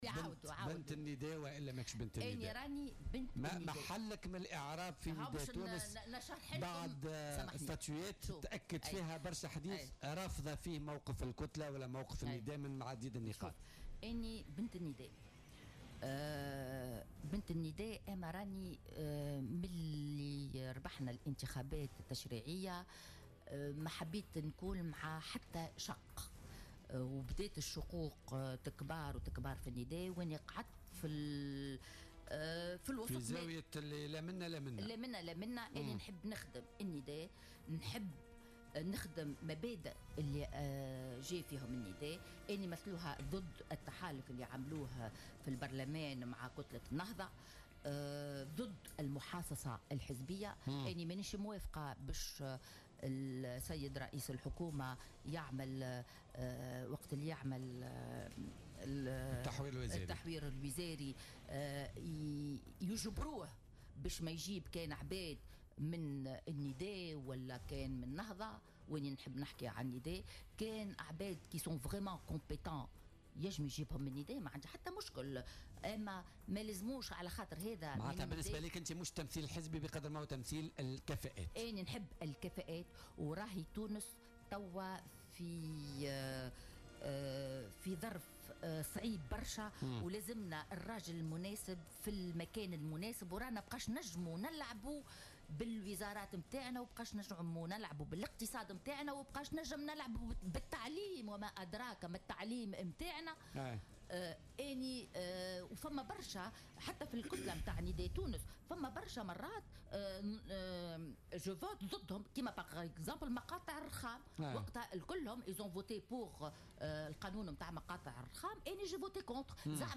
أكدت النائبة عن نداء تونس زهرة ادريس ضيفة بولتيكا اليوم الإثنين 21 أوت 2017 أنها بنت النداء و لكنها ابنة الشعب قبل الحزب على حد قولها.